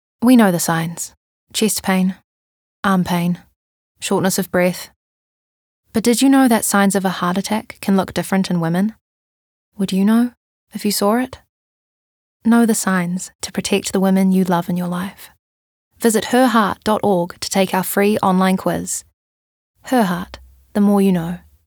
Her voice spans from warm and smooth to friendly and energetic, maintaining a sense of trustworthiness, sophistication, and eloquence that suits a variety of voice work.
sincere